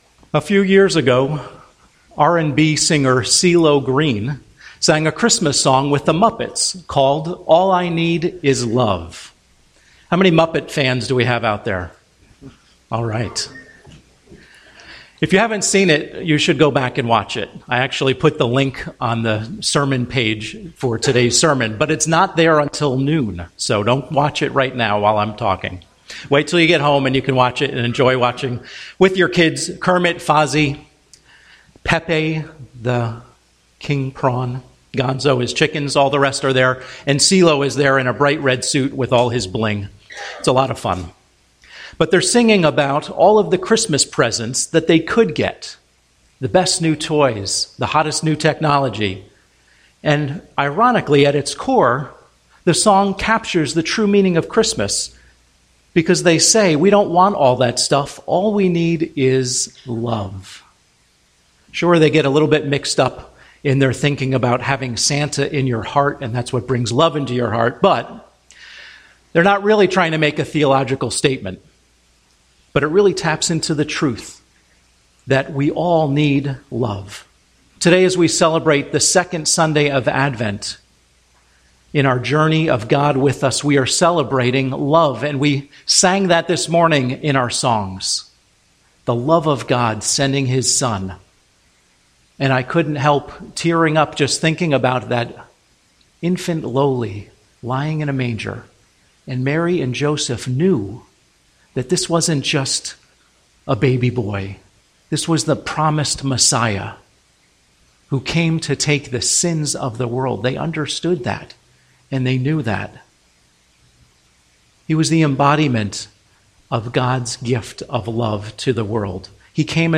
Home › Sermons › God With Us Brings Love